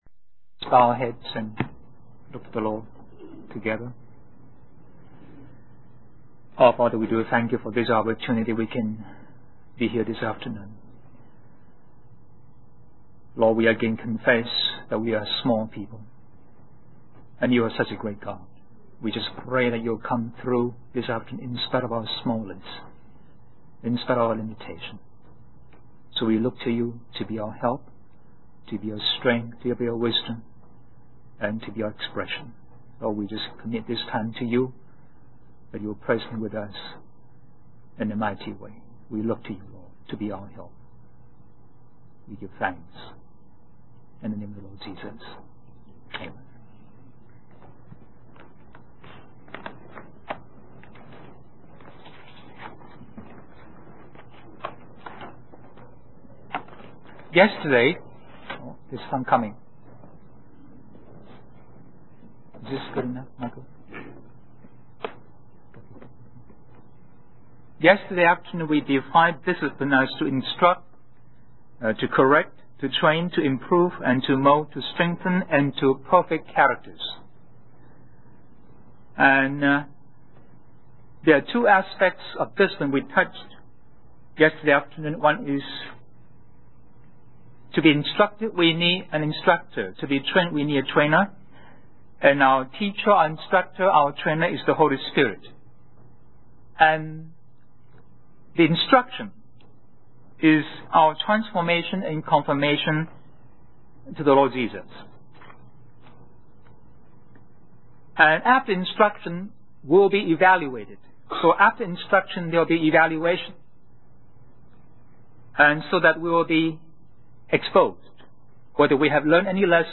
In this sermon, the speaker emphasizes the importance of ministers who preach the Word of God and encourages the listeners to imitate their faith. He highlights the need for discipline in studying the Bible and suggests reading through whole books for a better understanding.